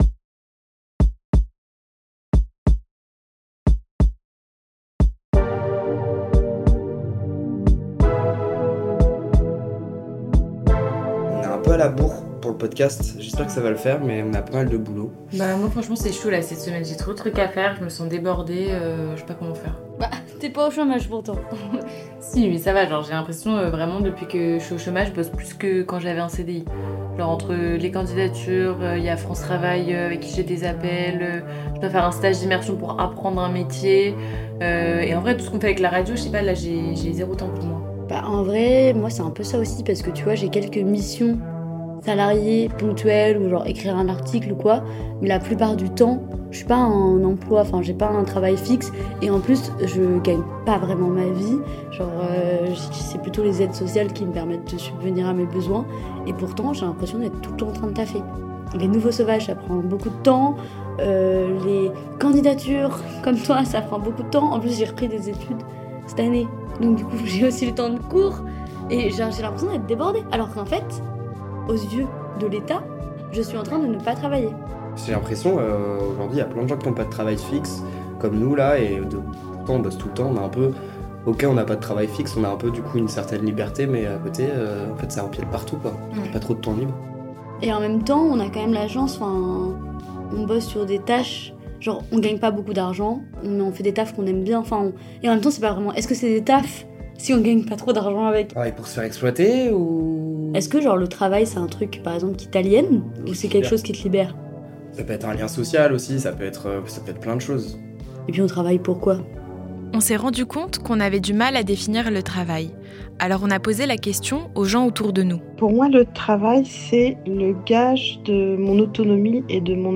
Vous entendrez une sociologue qui interroge la manière dont le travail est pensé et nommé, un reportage à la cantine des Gilets Jaunes de l’AERI à Montreuil, et une chercheuse qui invite à penser le travail autrement que par la seule réduction du temps de travail.